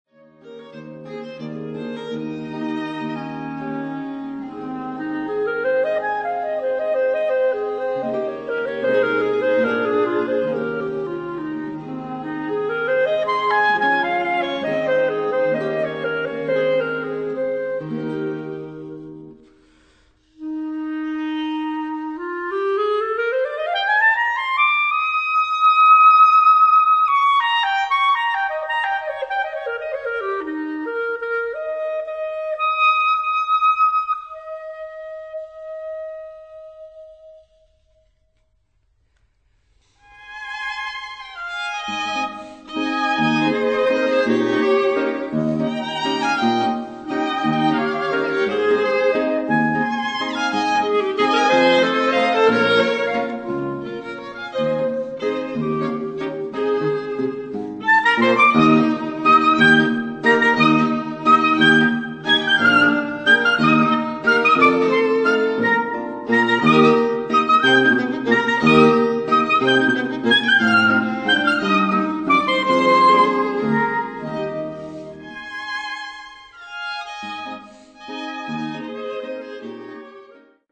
* Quartett mit G-Klarinette
Steinerner Saal, Musikverein Wien
Walzer * 6'35